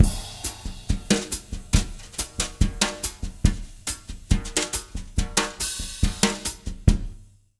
140BOSSA05-L.wav